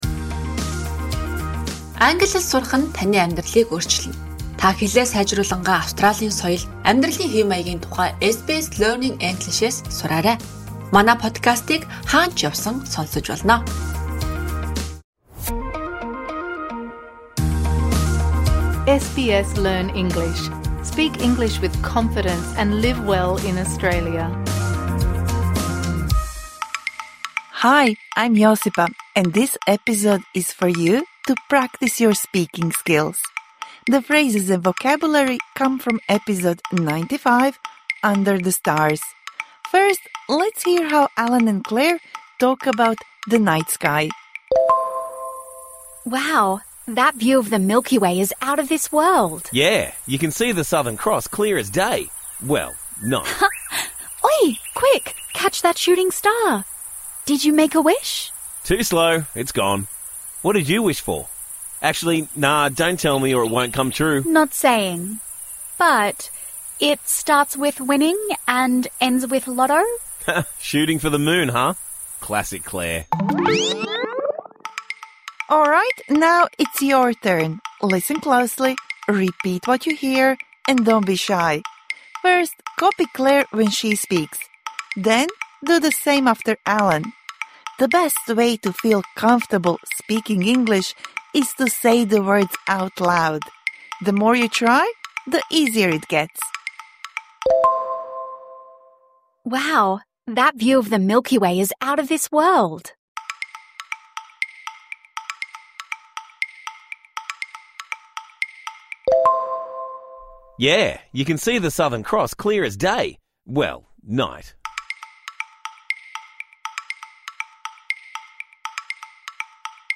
This bonus episode provides interactive speaking practice for the words and phrases you learnt in #95 Under the stars (Med).